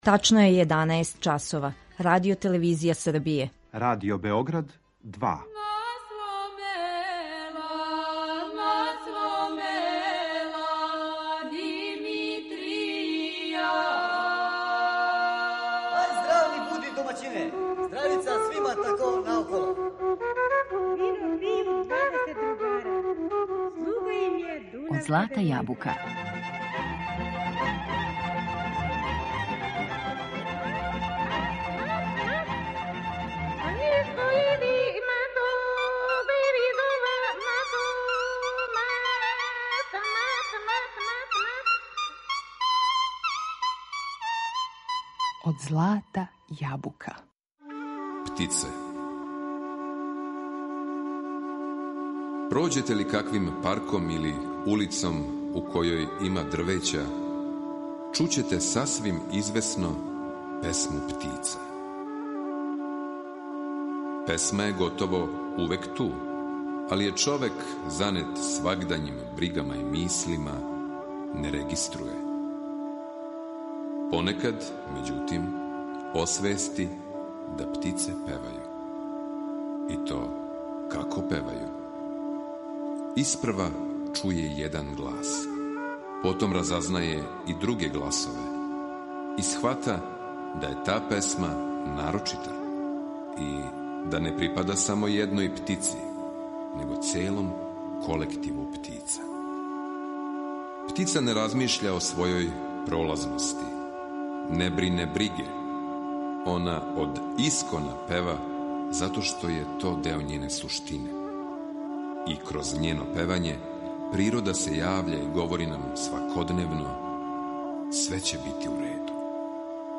традиционалне песме
вокали
фрула и тарабука
фрула, кавал и јерменски дудук
кларинет, гајде и кавал
македонска тамбура, саз и виолина
чалгија и тамбурашко чело
тапан